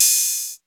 CYM XCHEEZ04.wav